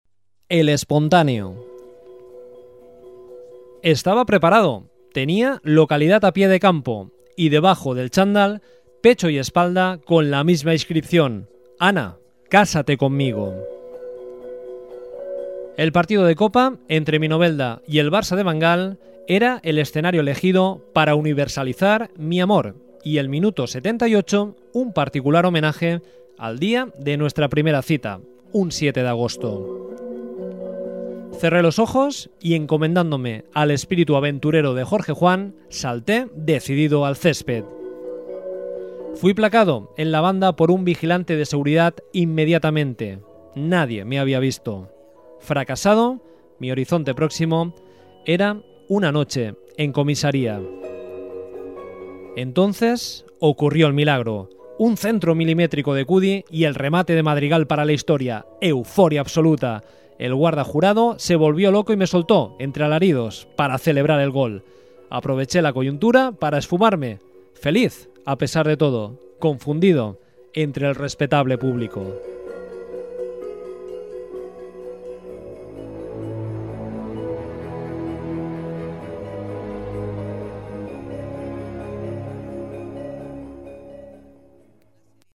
Listado de relatos teatralizados ( MP3 ):